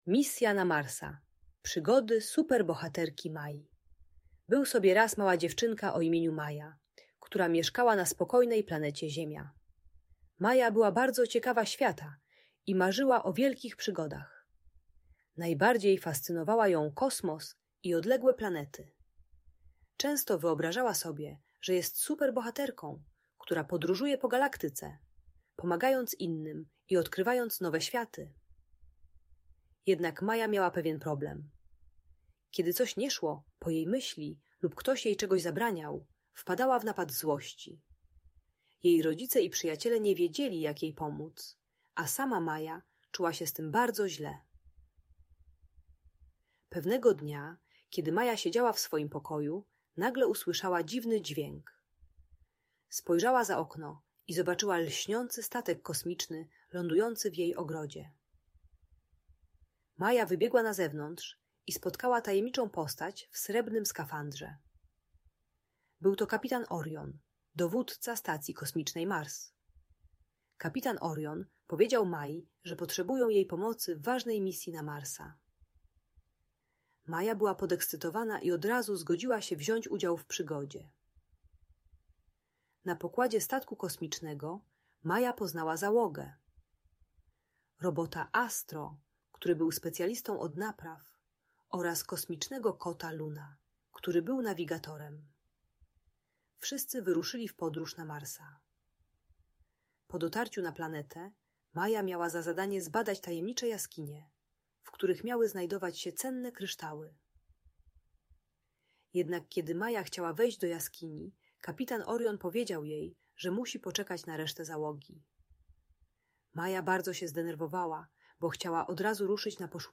Historia Super Bohaterki Mai: Misja na Marsa - Audiobajka